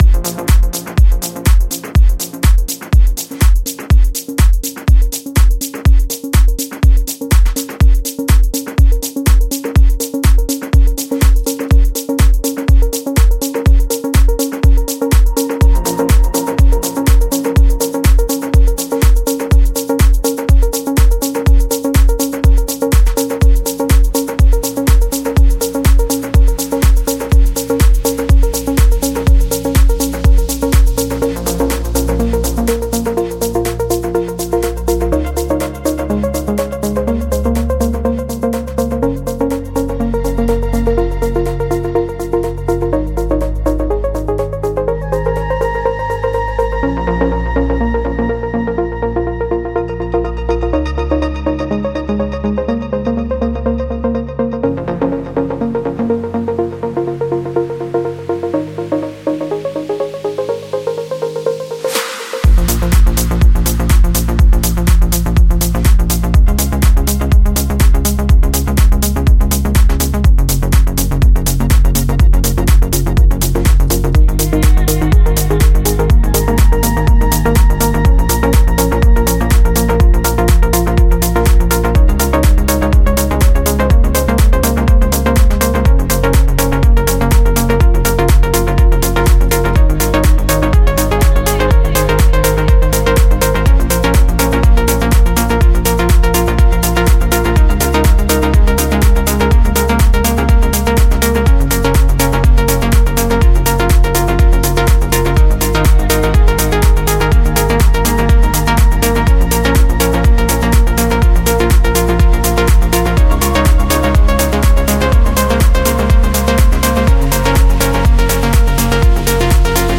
现在就学习如何以许多AnjunaDeep Artists的风格制作谐调丰富且旋律先进的Progressive House曲目。